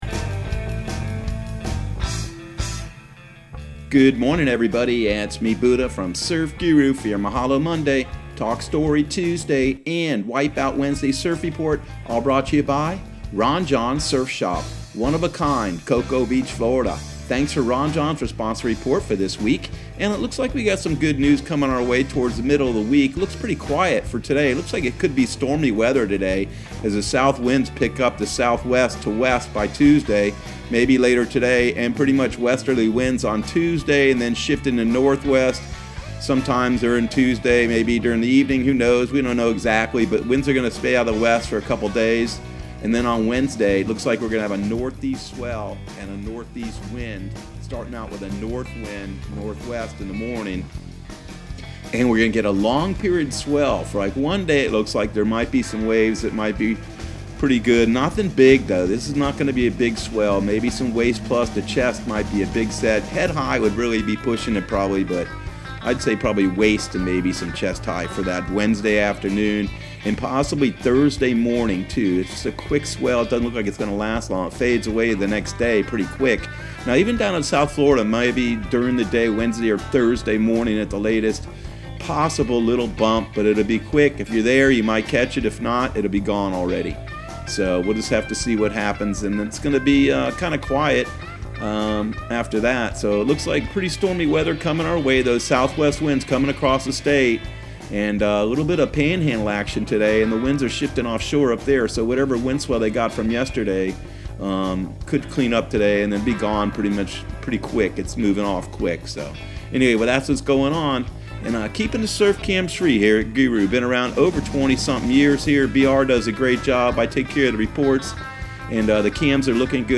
Surf Guru Surf Report and Forecast 04/20/2020 Audio surf report and surf forecast on April 20 for Central Florida and the Southeast.